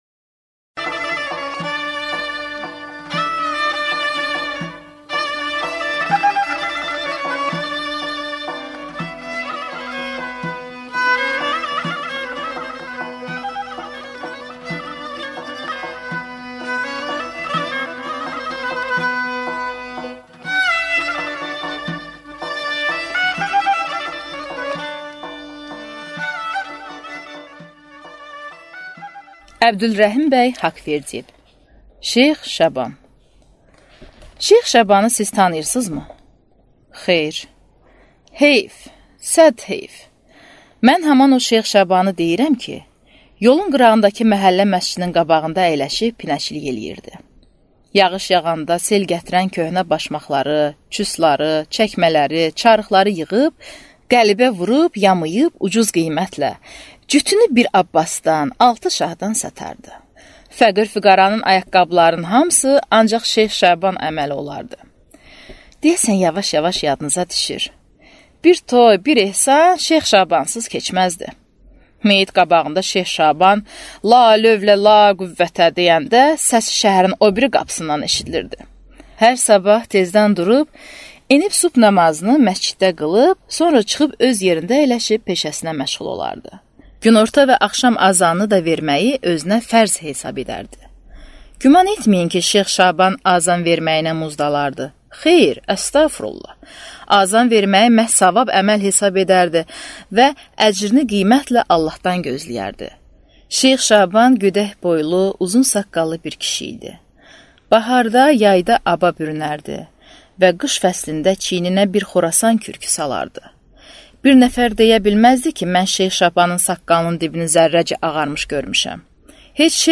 Аудиокнига Şeyx Şaban | Библиотека аудиокниг